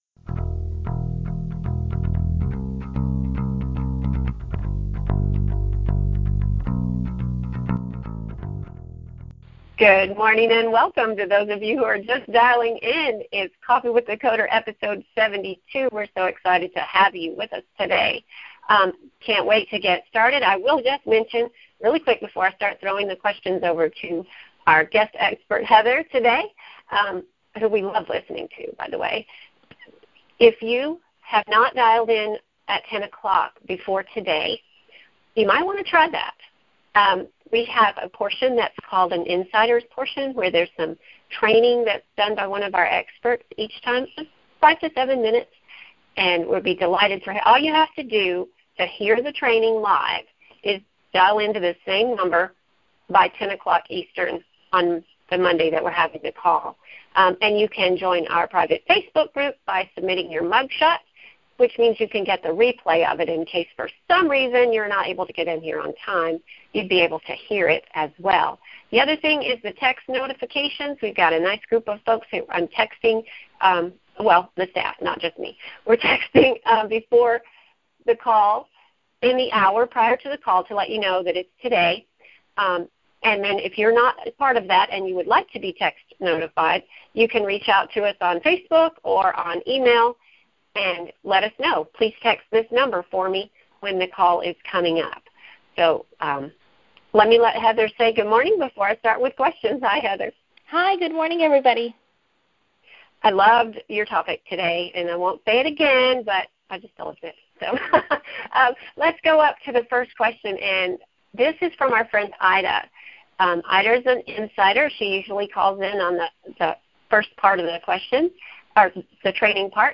Welcome to Coffee with the Coder®, our conversation